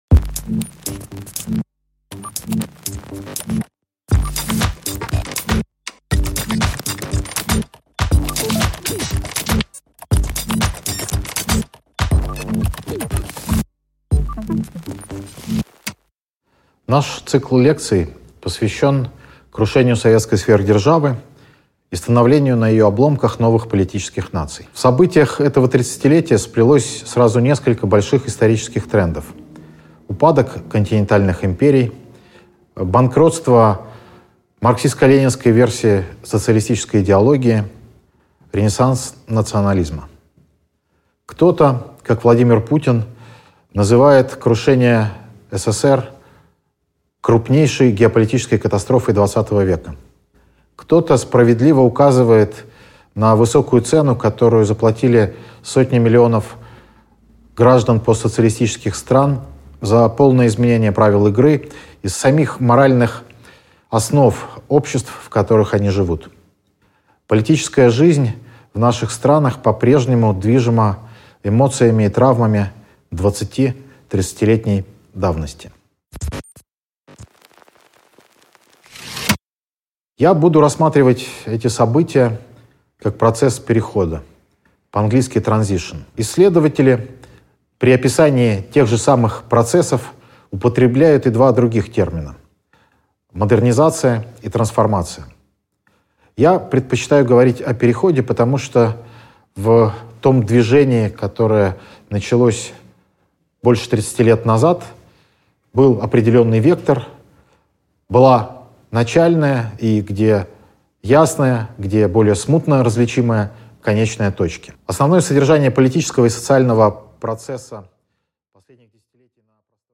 Аудиокнига 1984: Советский Союз накануне перемен. Что знал Оруэлл о реальном социализме?